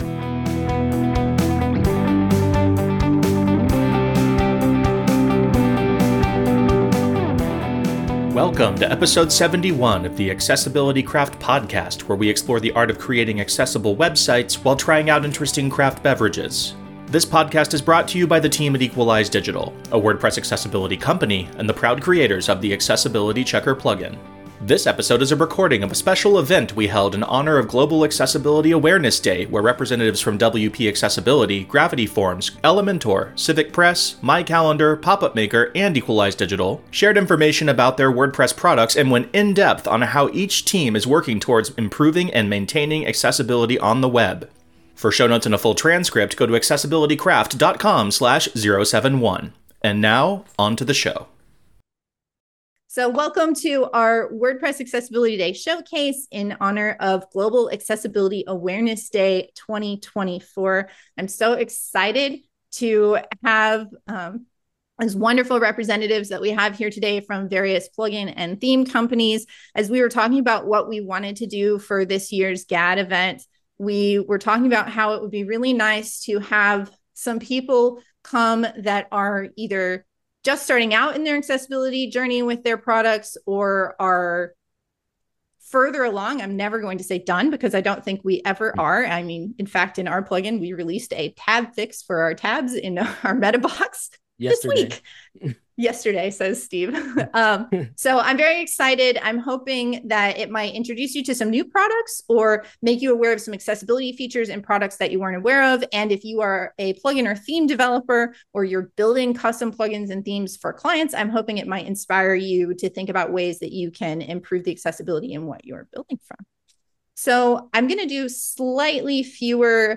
This episode is a recording of a special event we held in honor of Global Accessibility Awareness Day, where representatives from WP Accessibility, Gravity Forms, Elementor, CivicPress, My Calendar, Popup Maker, and Equalize Digital shared information about their WordPress products, and went in-depth into how each team is working towards improving and maintaining accessibility.